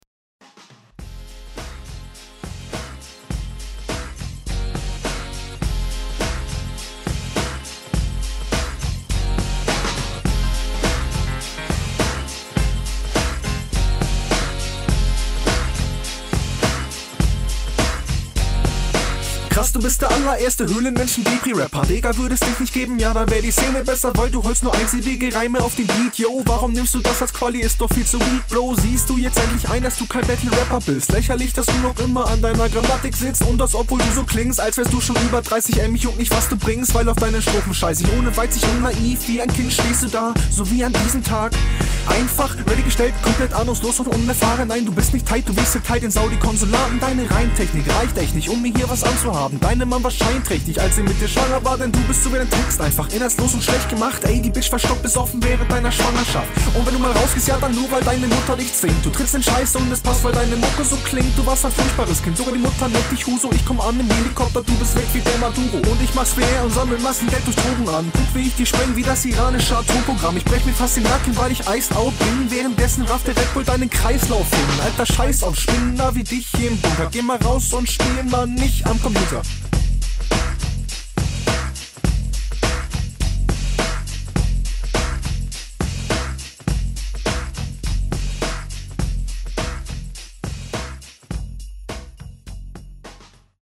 Moin, hast leider paar Verhaspler, der Beat ist okay aber Flowmäßig bist du eher gelangweilt …
Mische ist ausbaufähig, sonst durchaus Solide Runde.
Flow: Find ich gut, gute Stimme, ein paar schöne Triolen Technik: Manchmal gut, manchmal mittelmäßig, …